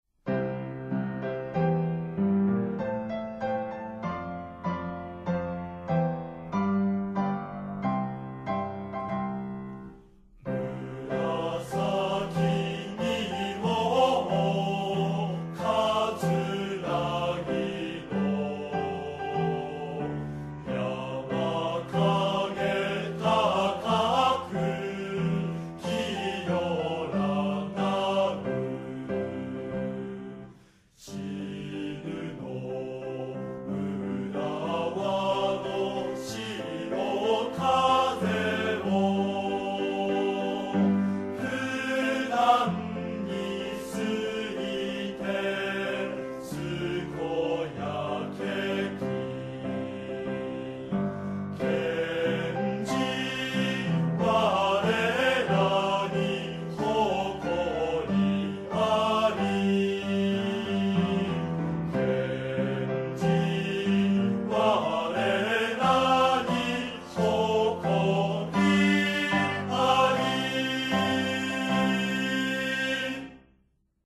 校歌